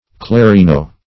clarino - definition of clarino - synonyms, pronunciation, spelling from Free Dictionary Search Result for " clarino" : The Collaborative International Dictionary of English v.0.48: Clarino \Cla*ri"no\, n. [It. a trumpet.]